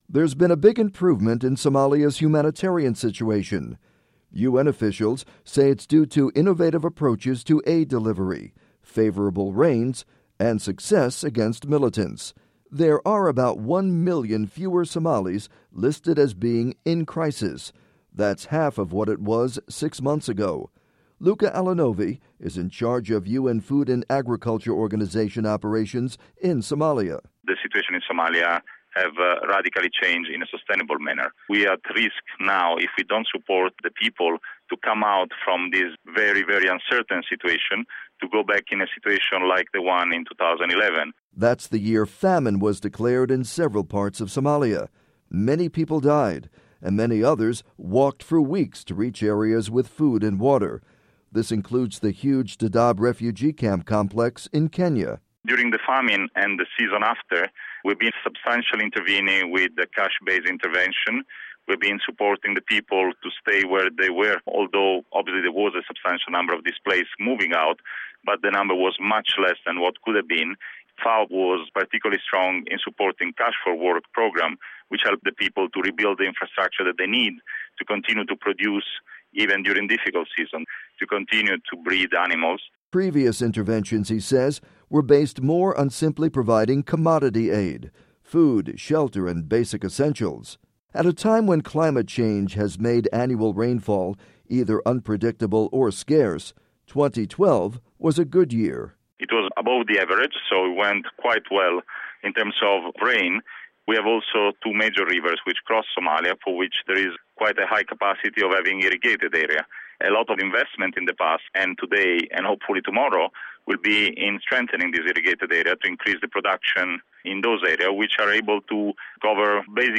report on Somalia